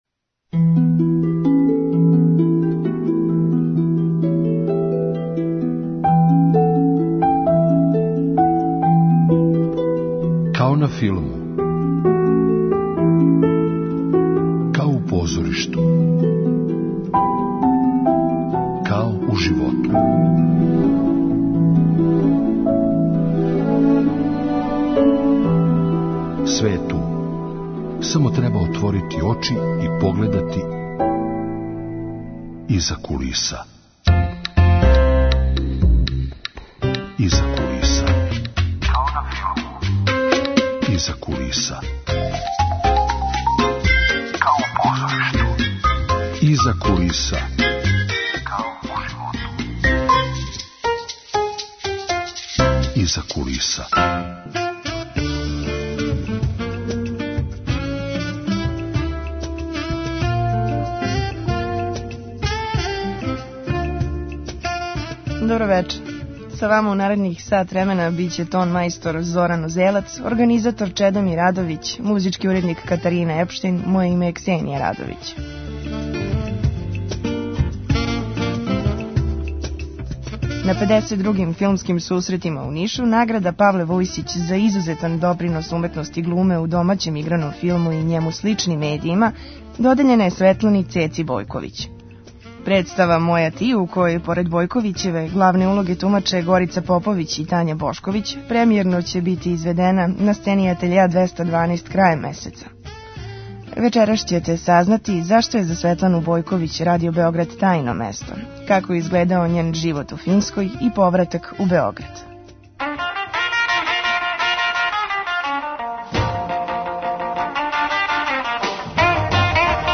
Гошћа : глумица Светлана Бојковић.